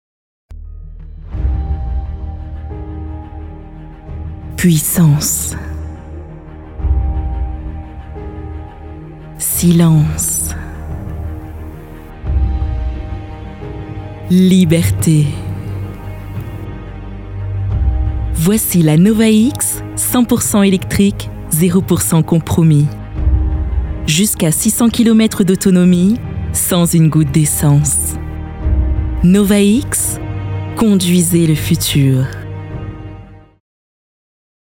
extrait pub gourmande
comédienne voix off